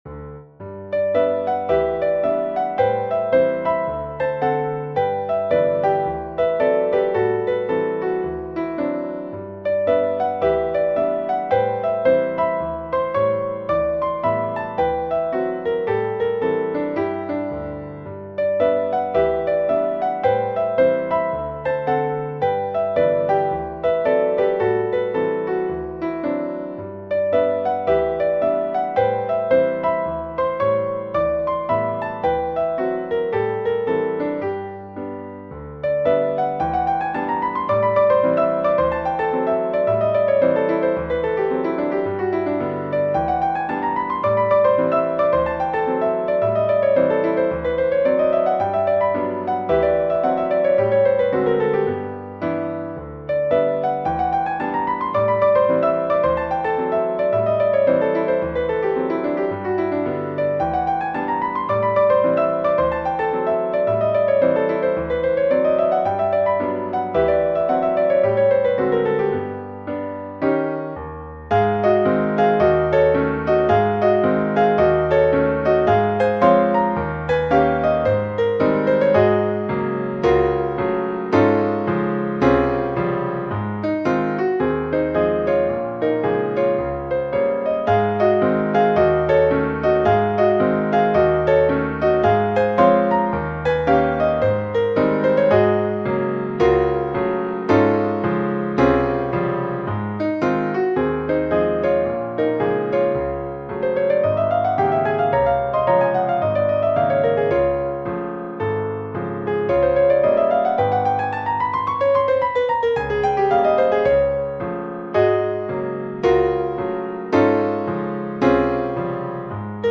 For solo Piano: